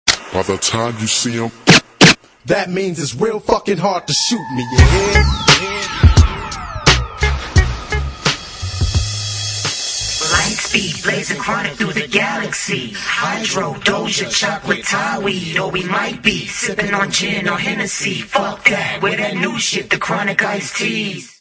Назад в RAP